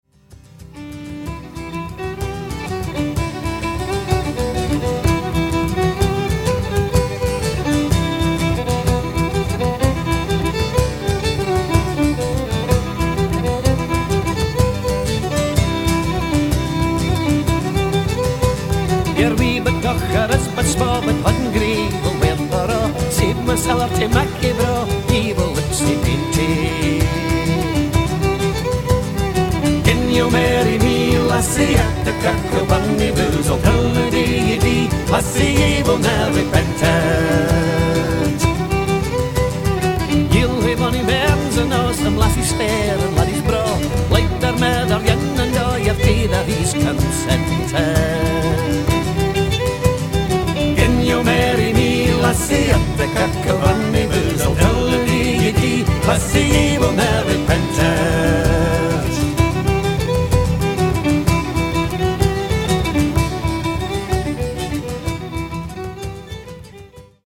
Country Folk & International Music